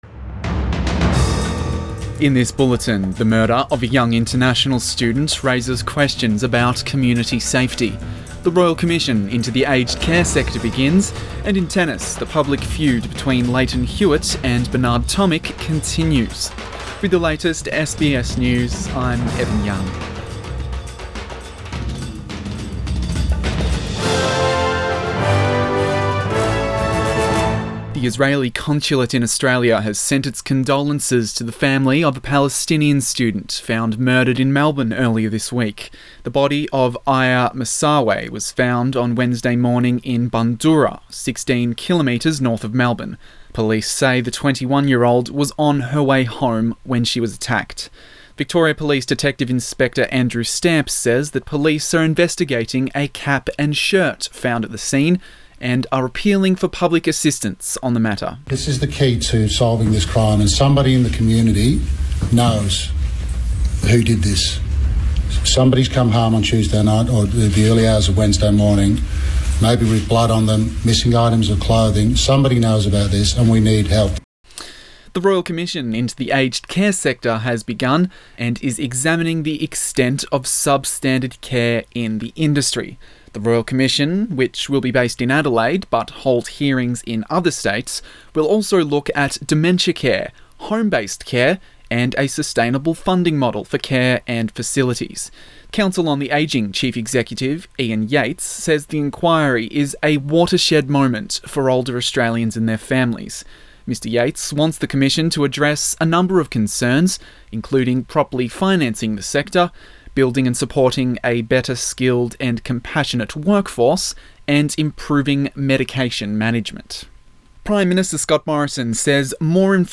Midday bulletin 18 January